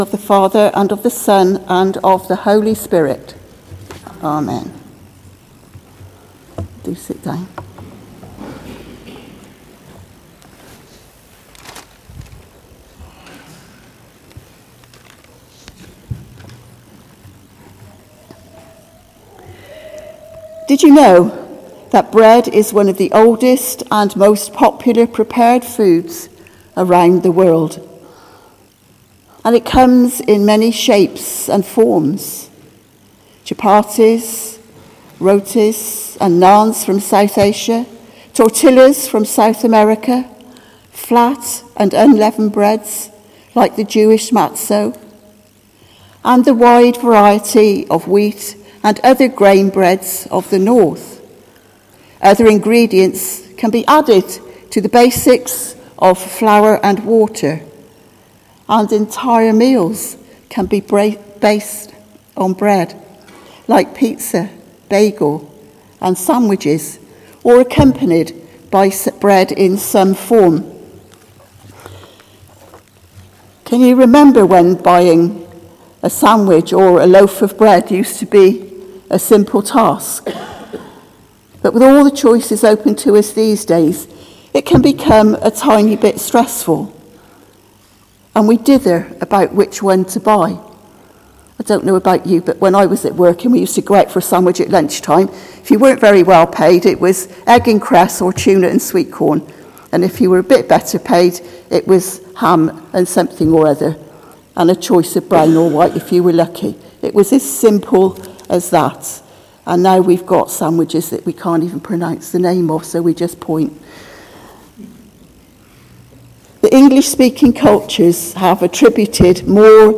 Sermon: Bread of Life | St Paul + St Stephen Gloucester